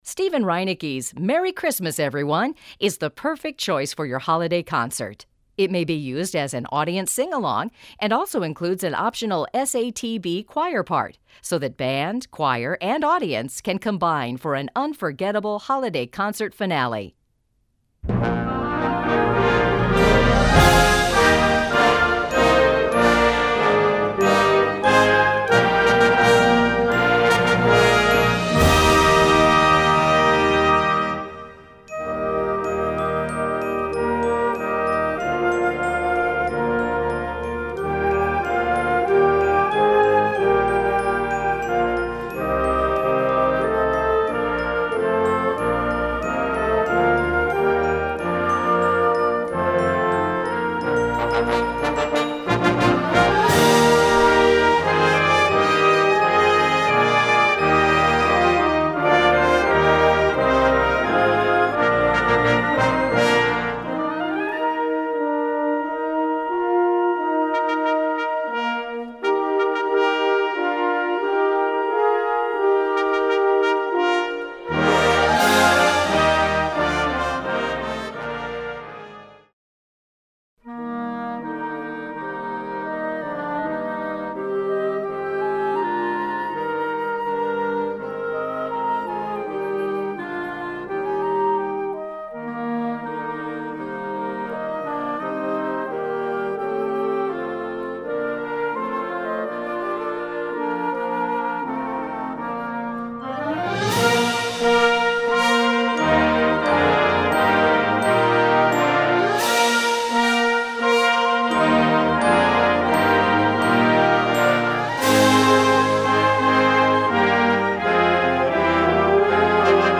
Gattung: A christmas sing-along
Besetzung: Blasorchester